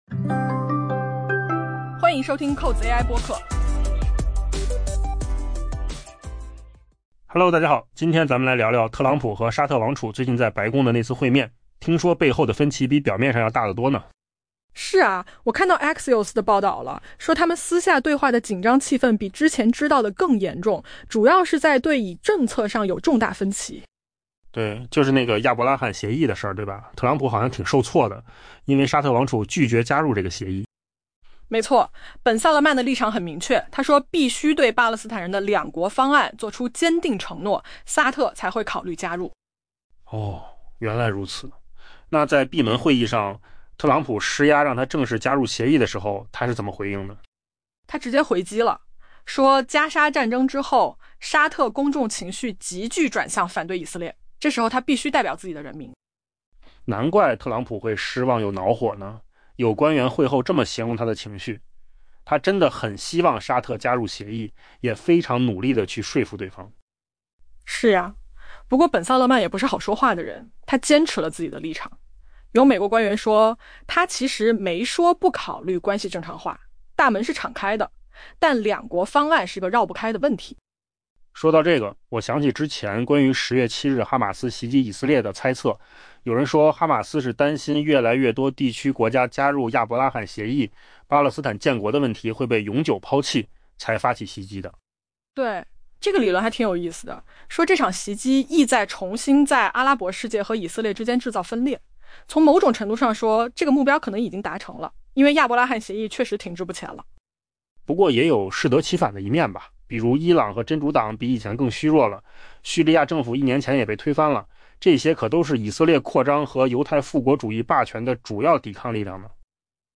【文章来源：金十数据】AI播客：换个方
AI 播客：换个方式听新闻 下载 mp3 音频由扣子空间生成 Axios 本周报道了美国总统特朗普与沙特王储穆罕默德·本·萨勒曼近期在白宫会晤的一些新细节， 称两位领导人私下对话中的紧张气氛比此前所知的更为严重，双方在对以政策上存在一些重大分歧。